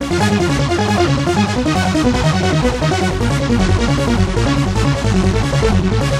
踢球2为硬式风格160BPM
描述：踢腿2用于Hardstyle 160BPM。
标签： 160 bpm Hardstyle Loops Bass Guitar Loops 260.59 KB wav Key : Unknown
声道立体声